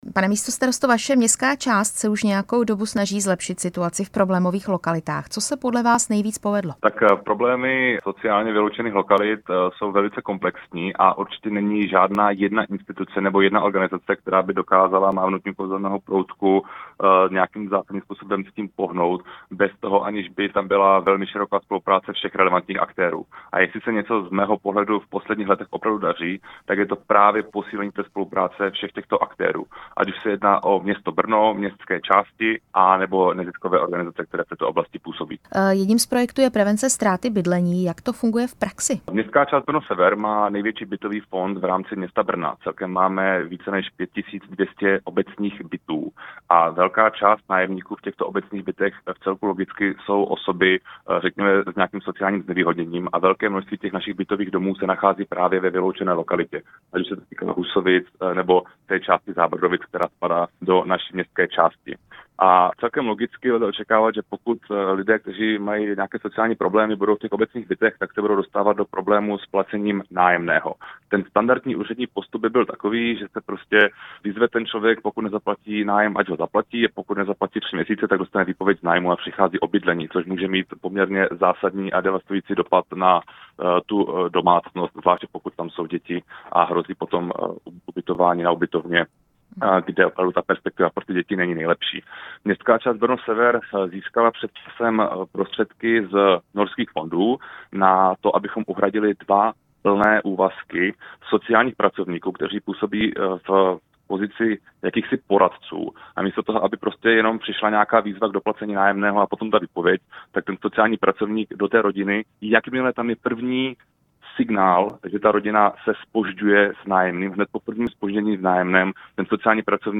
Téma jsme ve vysílání Rádia Prostor probírali s místostarostou Martinem Baselem z ODS.
Rozhovor s místostarostou městské části Brno-sever Martinem Baselem (ODS)